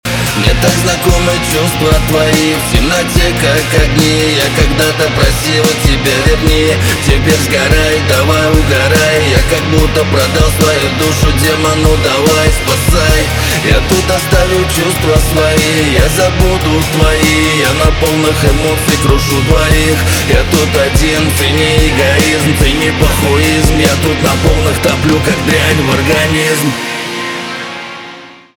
русский рэп
грустные , барабаны , гитара